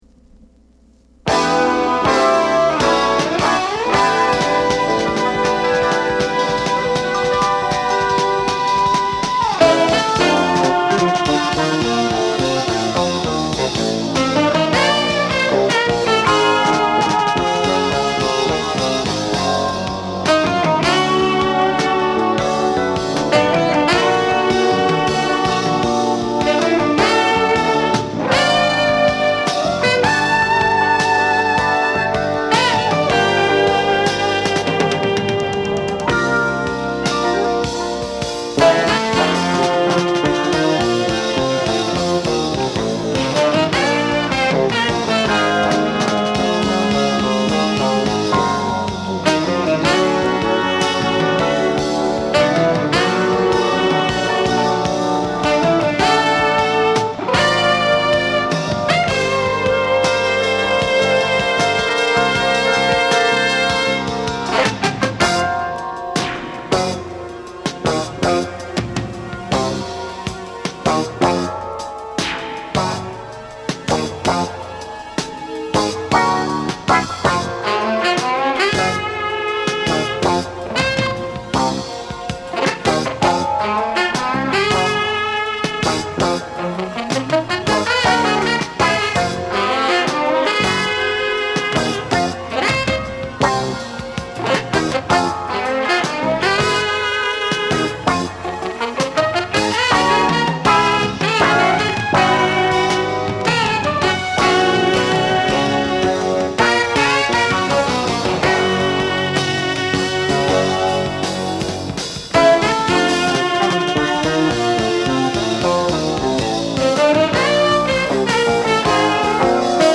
Genre: Jazz Fusion / Funk Rock
テナーサックス
キーボード
ベース
ドラムス